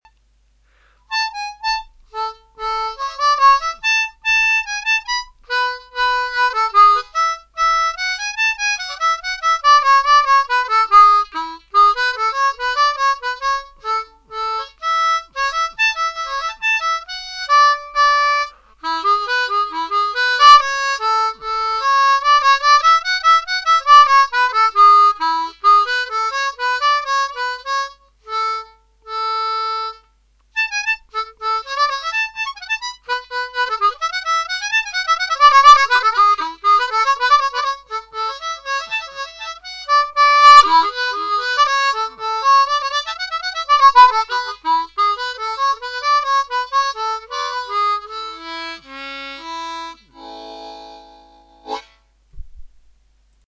A major (and B major) diatonic harp(s) in 1st position…but you can use any two harps tuned a tone apart
The Good Doctor’s arrangement (also on an A major diatonic)